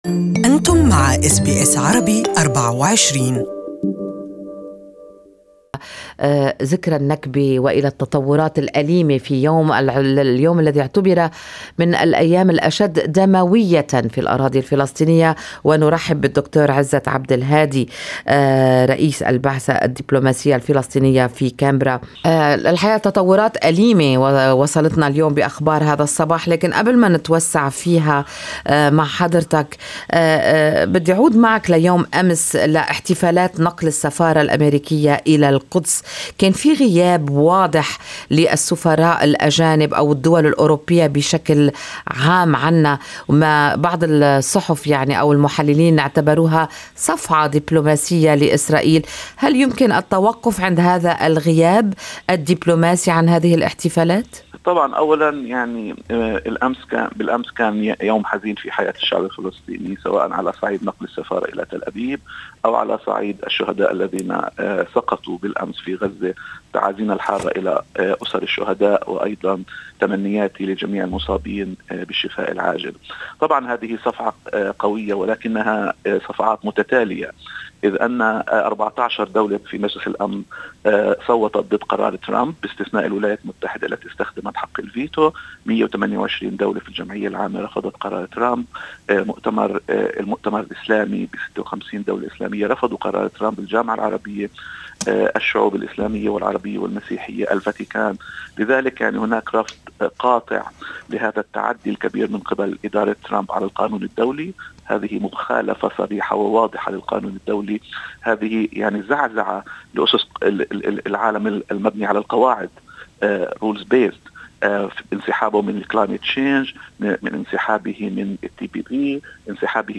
وقال رئيس البعثة الدبلوماسية الفلسطينية لأستراليا ونيوزيلندا الدكتور عزت عبد الهادي في حديث لاس بي اس عربي أن السلطة الوطنية الفلسطينية لم تعد تعتبر الولايات المتحدة راعياً لعملية السلام والتي لا تزال متوقفة منذ 3 سنوات واشار الى نية عقد مؤتمر دولي للسلام بمشاركة دول عدة بهدف الضغط على اسرائيل لتحريك عملية السلام.